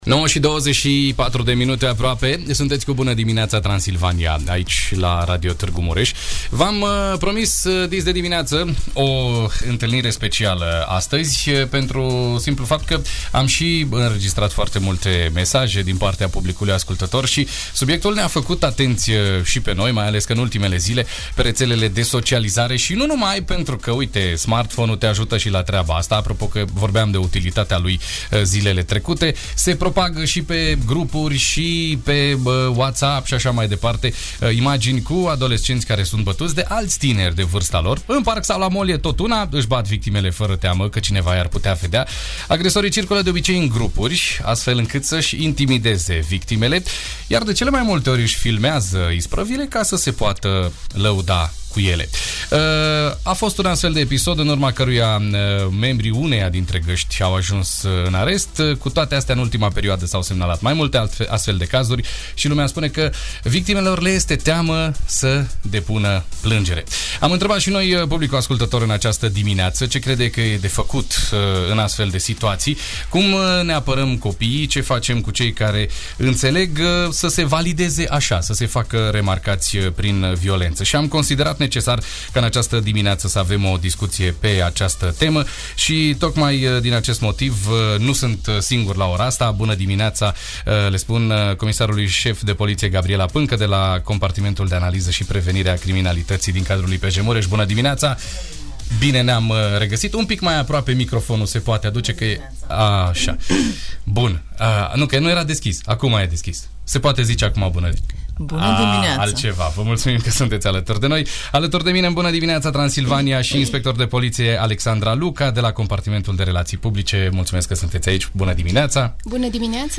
Violența în rândul tinerilor, dezbătută la Radio Tg.Mureș - Radio Romania Targu Mures